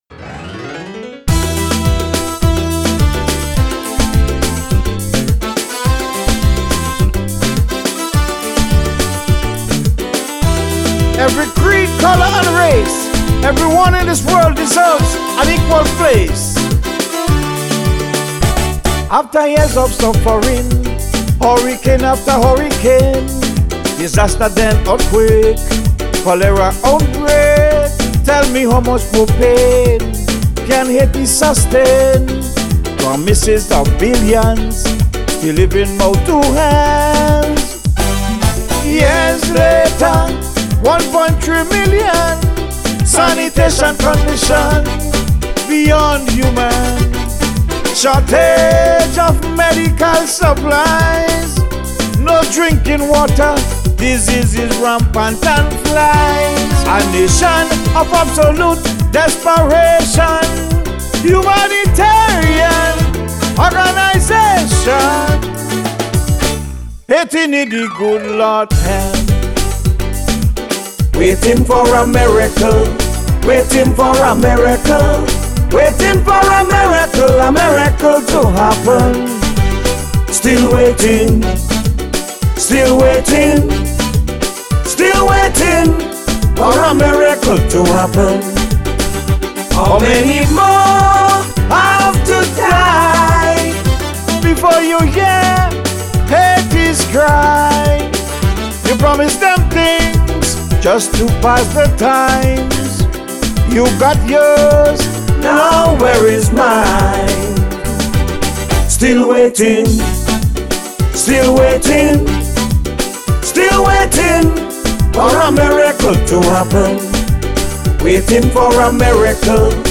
Calypso (kaiso)